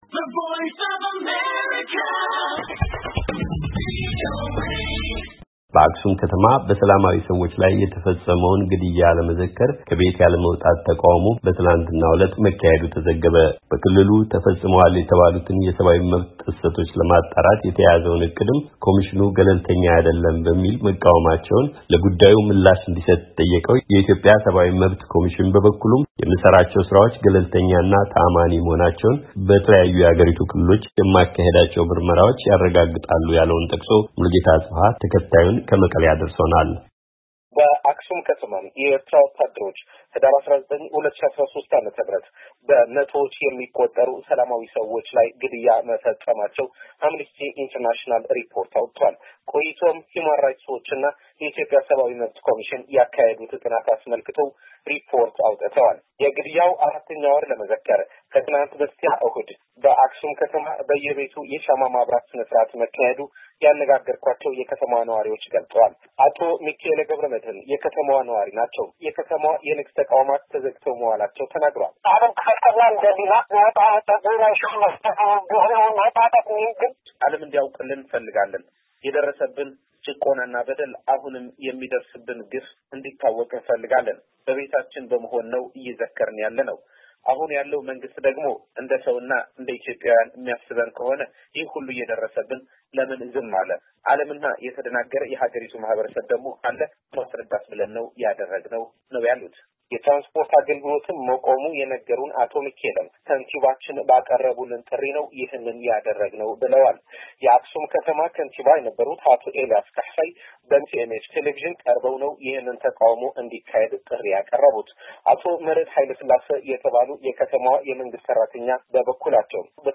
በክልሉ ተፈፅመዋል የተባሉትን የሰብዓዊ መብት ጥሰቶች ለማጣራት የተያዘውን ዕቅድንም ኮሚሽኑ ገለልተኛ አይደለም በሚል መቃወማቸውን፣ ለጉዳዩ ምላሽ እንዲሰጥ የጠየቀው የኢትዮጵያ የሰብዓዊ መብት ኮሚሽን በበኩሉም የምሰራቸው ሥራዎች ገለልተኛ እና ተዓማኒ መሆናቸውን በተለያዩ የሀገሪቱ ክፍሎች የማካሂዳቸው ምርመራዎች ያለውን ጠቅሶ ዘጋቢያችን አድርሶናል።